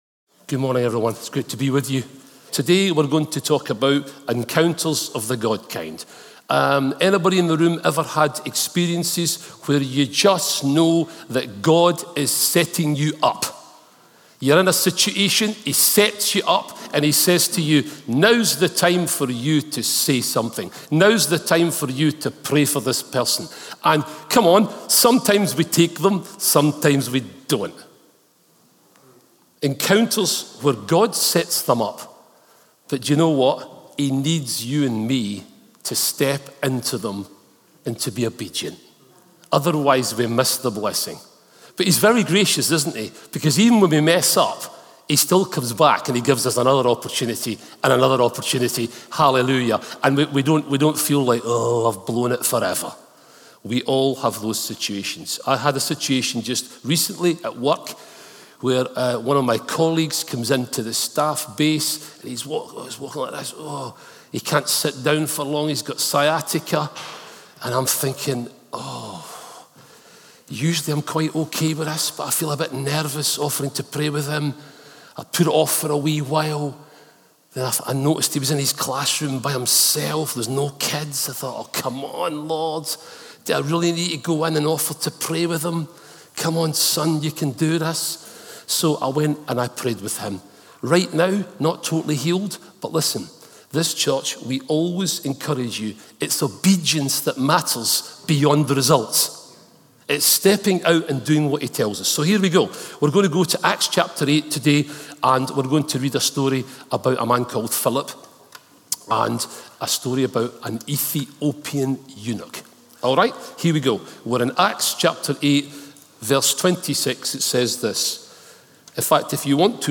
Religion Christianity Redeemer Church Colchester Sermons Admins Redeemer Church Colchester RCC Content provided by Admin and Redeemer Church Colchester.